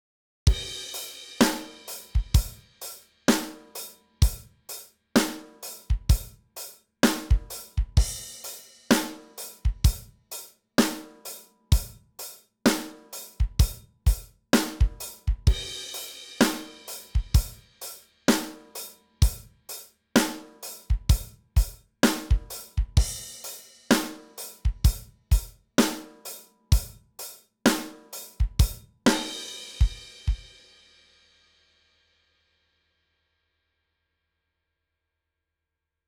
28（97ページ）ベートーヴェン・９　ドラム
28_Beethoven-Drums.wav